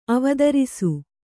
♪ avadarisu